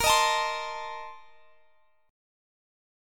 BbM7sus2sus4 Chord
Listen to BbM7sus2sus4 strummed